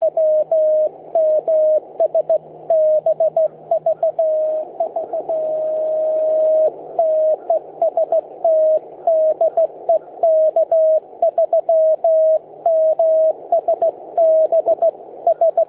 > 80M TX:  almost no drift, nice tone heard on ICOM RX.
> Here?s an on-air recording my friend made: